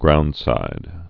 (groundsīd)